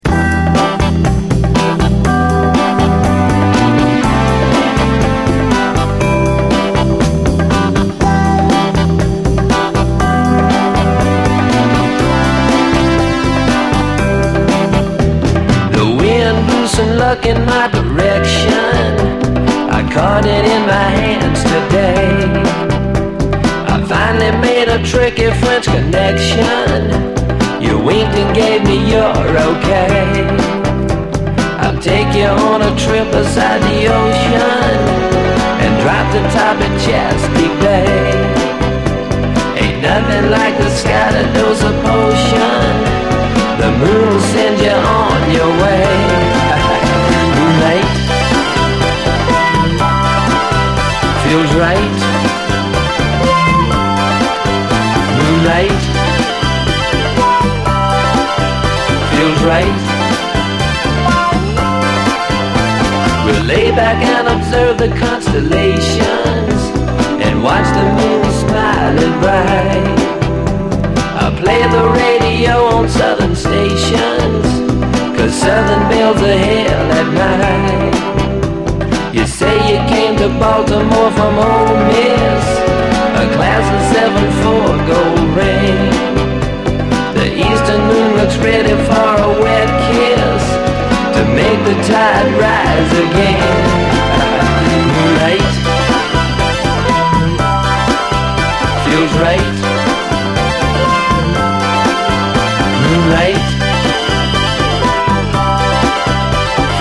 ピュンピュン音入りモダン・ソウル
クロスオーヴァー・ソウル
ブラック・サントラ風でドラマティック＆ファンキーな